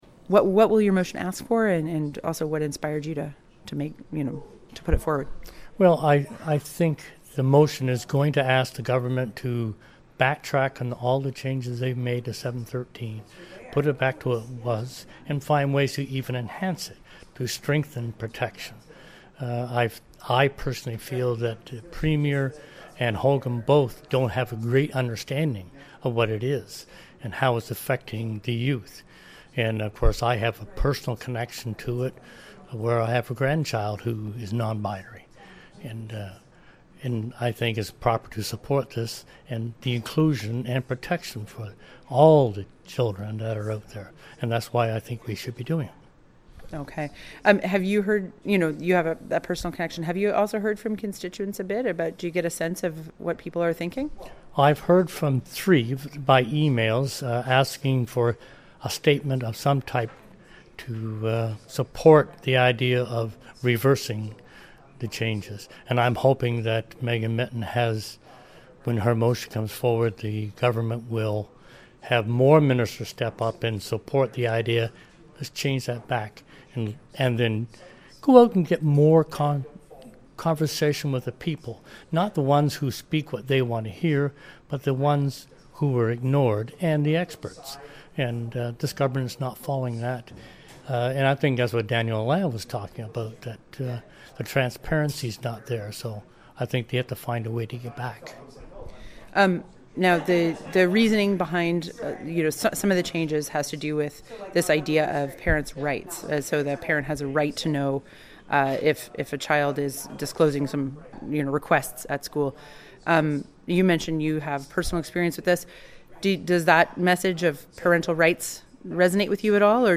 CHMA caught up with Tower after Tuesday’s meeting: